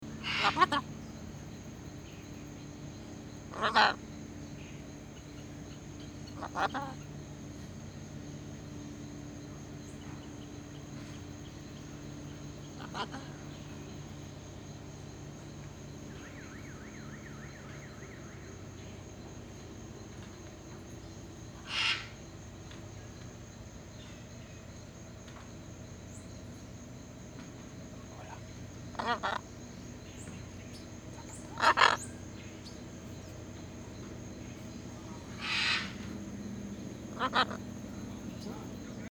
Elle pousse alors son cri d'attaque, un strident "Ola Zapata", et vise d'un oeil fixe mes orteils.